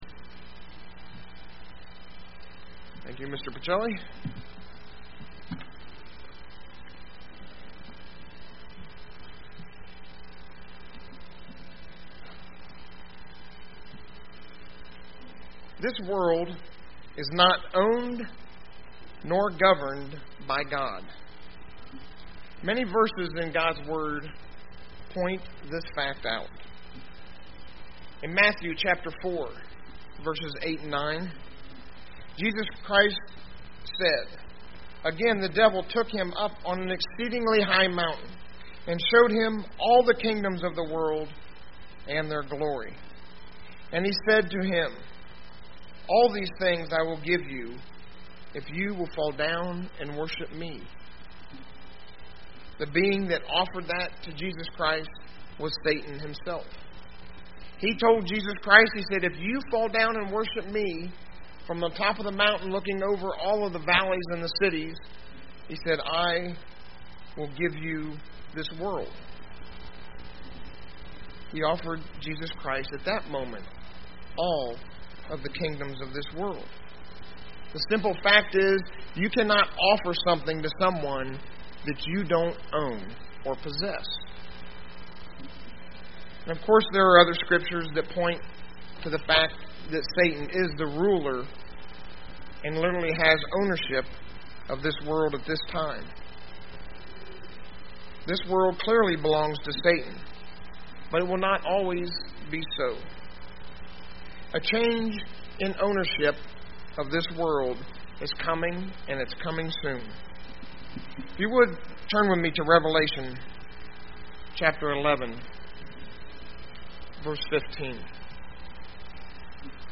UCG Sermon Notes Notes: Rev. 11:15 → It’s not a temporary change that is coming, but a permanent one.